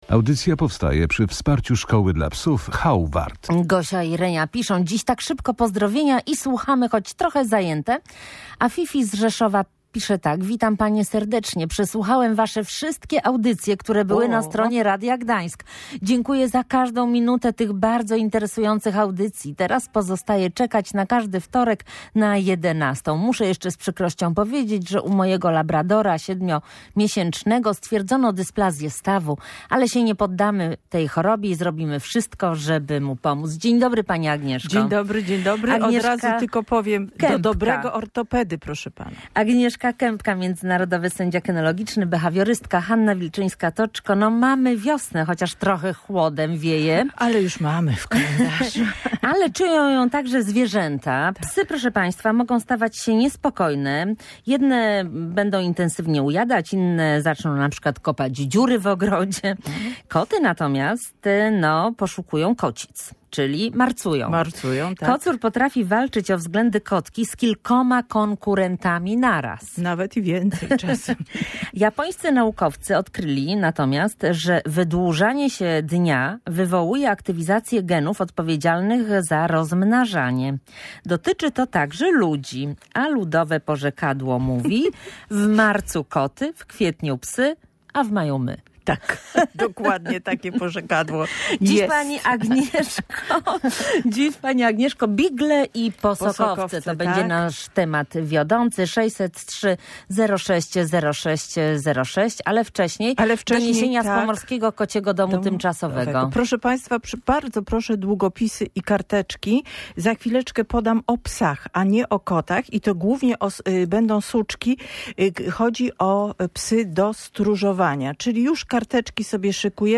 Behawiorystka i międzynarodowy sędzia kynologiczny opowiadała między innymi o psach rasy beagle.